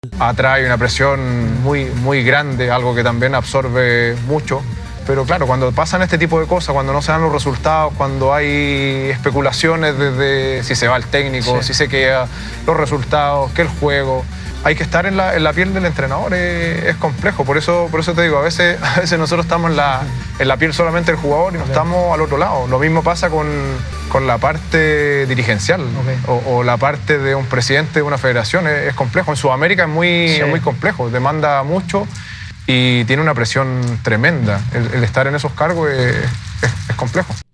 (Claudio Bravo, capitán de la selección de Chile, en diálogo con TNT Sports de Santiago)